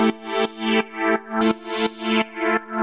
Index of /musicradar/sidechained-samples/170bpm
GnS_Pad-alesis1:4_170-A.wav